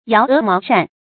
發音讀音
成語簡拼 yems 成語注音 ㄧㄠˊ ㄜˊ ㄇㄠˊ ㄕㄢˋ 成語拼音 yáo é máo shàn 發音讀音 常用程度 常用成語 感情色彩 中性成語 成語用法 作謂語、定語、賓語；指出謀劃策 成語結構 動賓式成語 產生年代 當代成語 近義詞 搖羽毛扇 成語例子 我倒是覺得敬軒的那位搖鵝毛扇子的軍師，生得鷹鼻子鷂眼，不是個善良家伙。